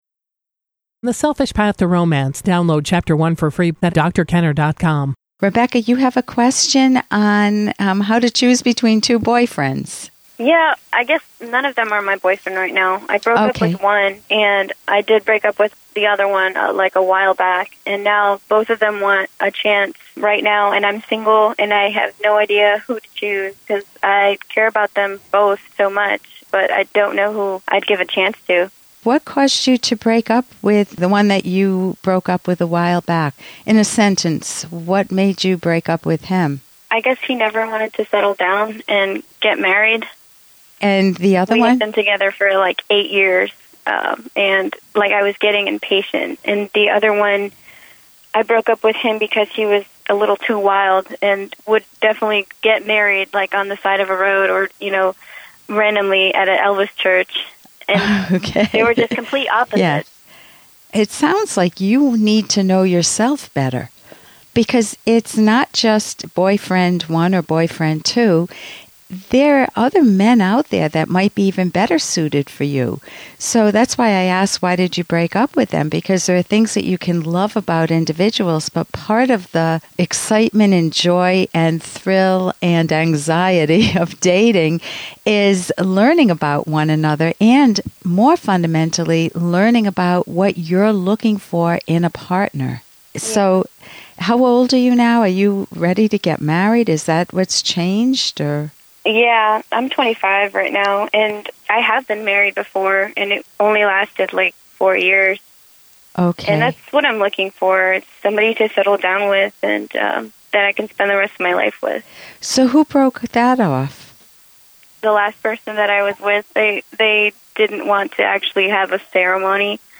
Episode from The Rational Basis of Happiness® radio show